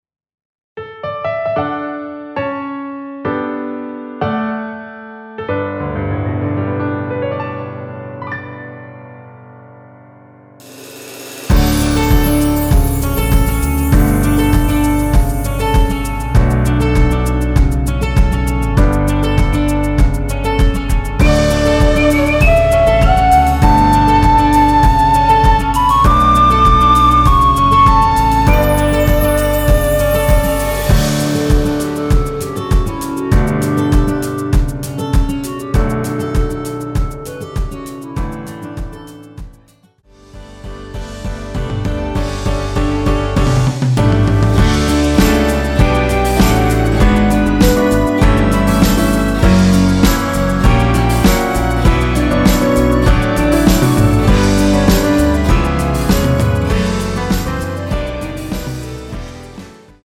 원키에서(-6)내린 멜로디 포함된 MR입니다.(미리듣기 확인)
앞부분30초, 뒷부분30초씩 편집해서 올려 드리고 있습니다.
중간에 음이 끈어지고 다시 나오는 이유는